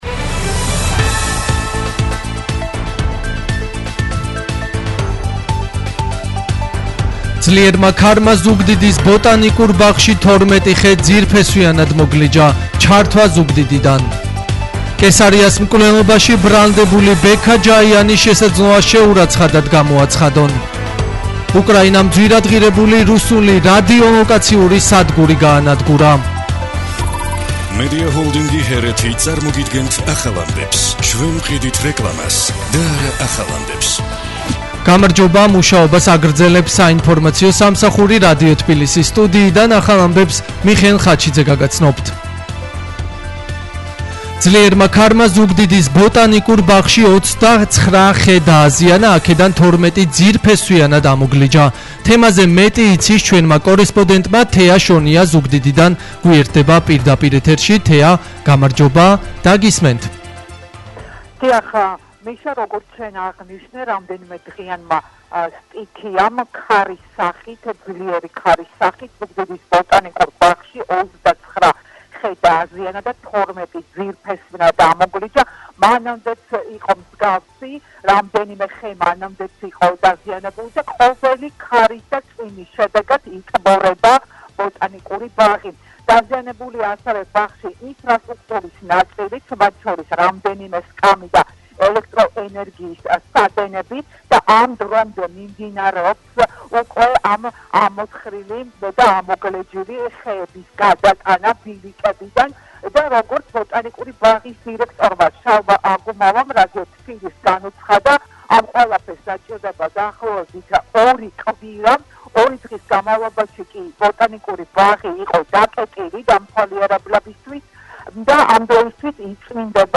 ახალი ამბები 17:00 საათზე